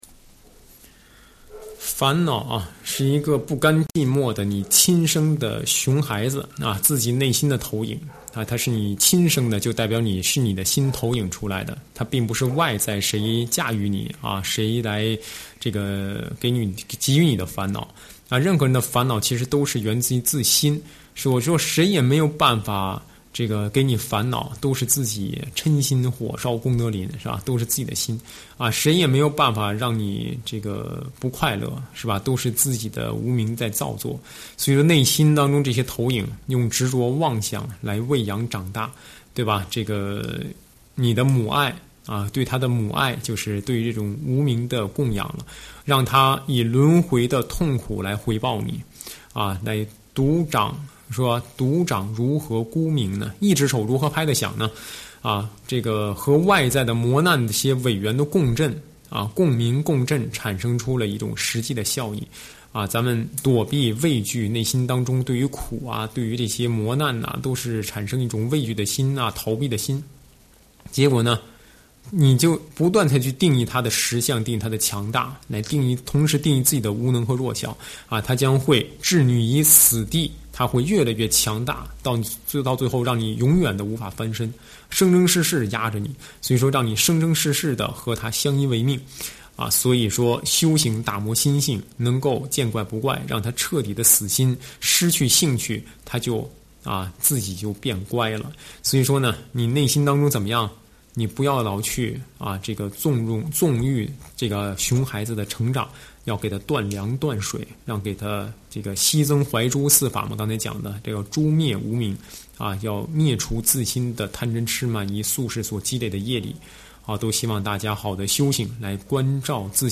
上师语音开示